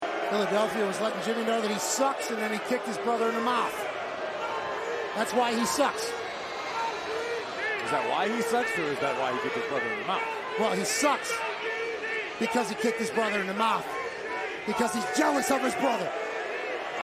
Pat McAfee and Corey Graves took advantage of the lull in the action to have a chicken-and-egg debate about